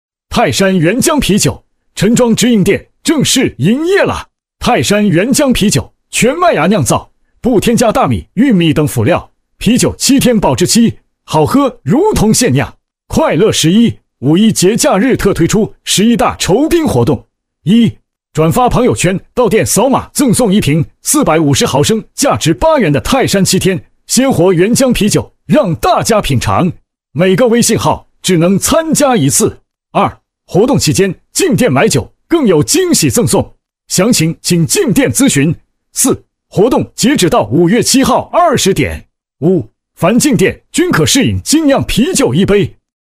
【激情促销男】